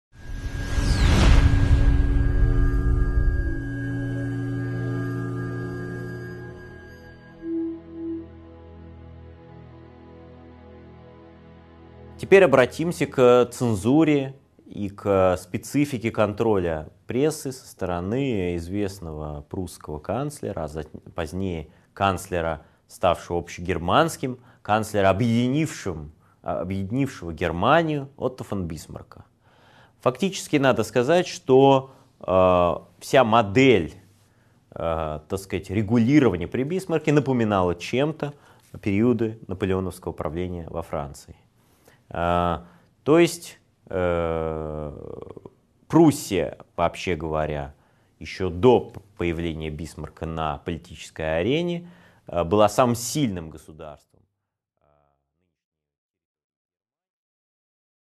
Аудиокнига 3.9 Цензура при Бисмарке | Библиотека аудиокниг